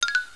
Sound Effects
COLLIDE.WAV
collide.wav